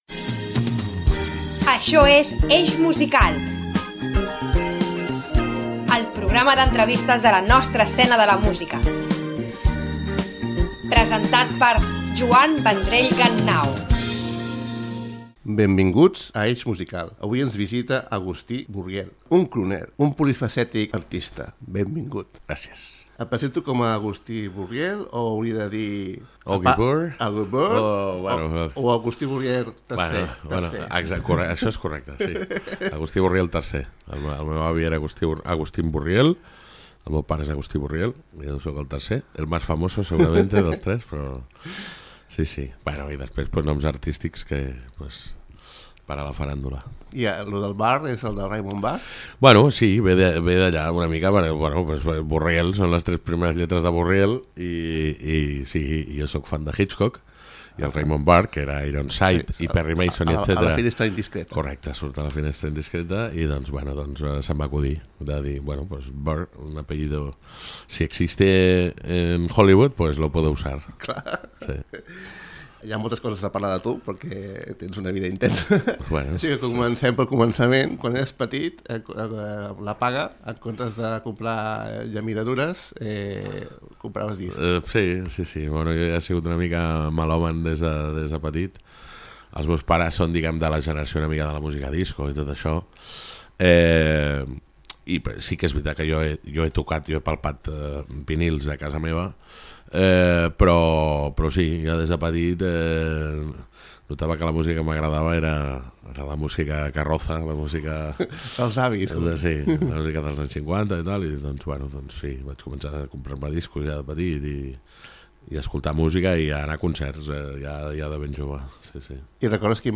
Acompanyat sempre de la seva guitarra, toca un tema a petició del presentador.